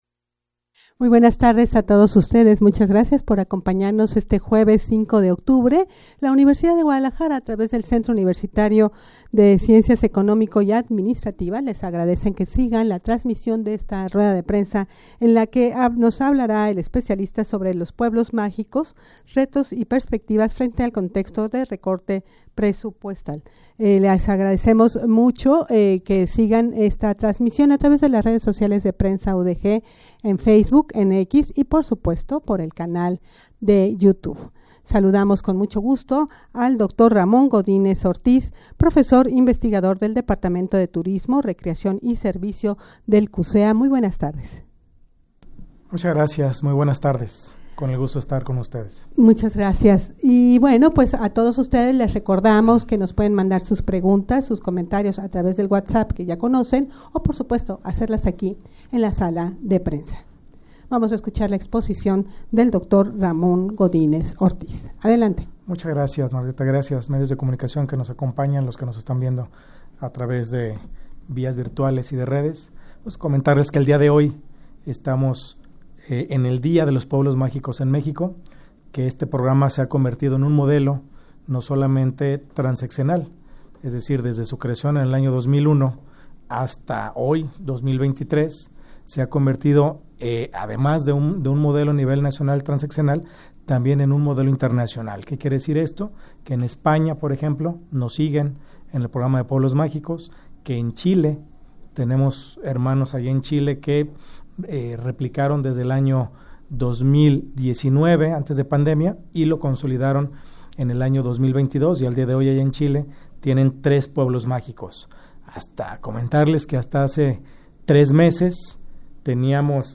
rueda-de-prensa-en-donde-especialistas-del-cucea-analizaran-los-retos-y-perspectivas-de-los-pueblos-magicos.mp3